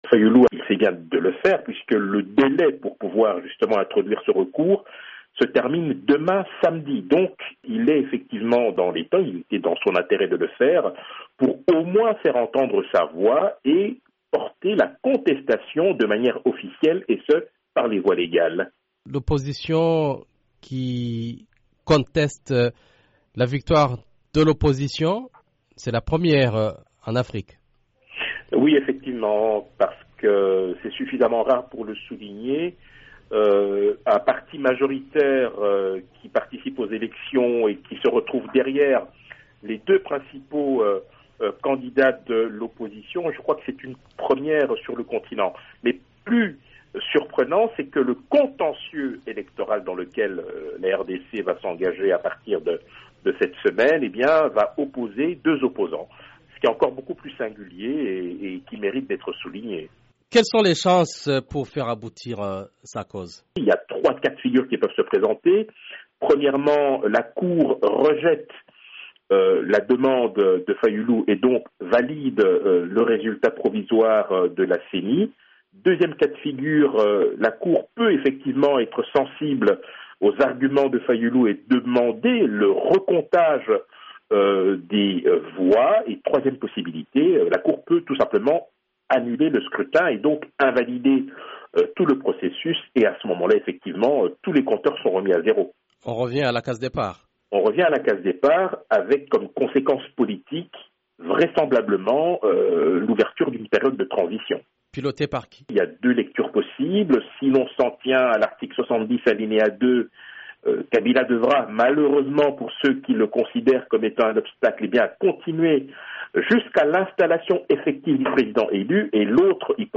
fait remarquer l’analyste politique